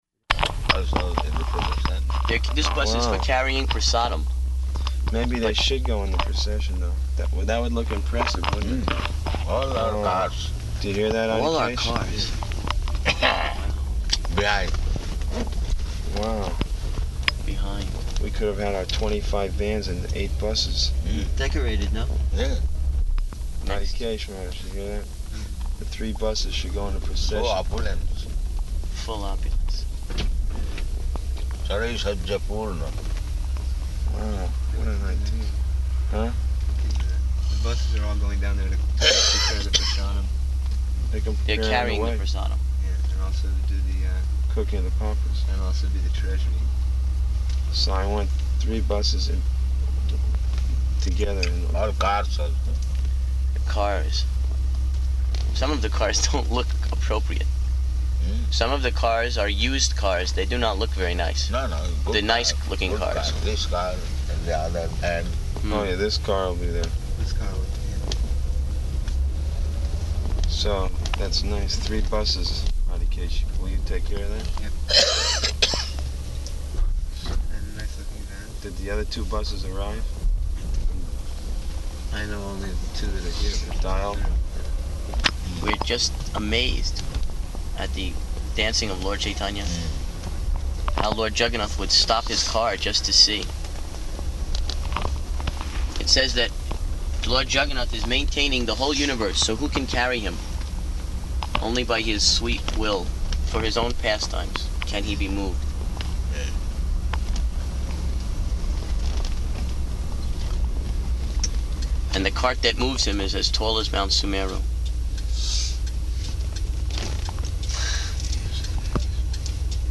Morning Walk --:-- --:-- Type: Walk Dated: July 18th 1976 Location: New York Audio file: 760718MW.NY.mp3 [in car] Prabhupāda: ...also in the procession?